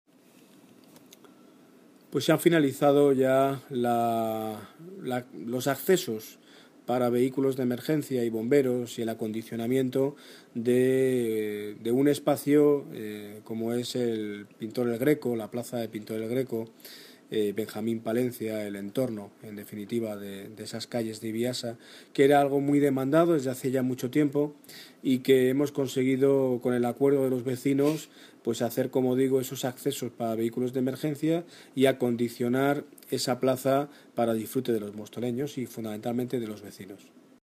Audio - Daniel Ortiz (Alcalde de Móstoles) Sobre Pintor Greco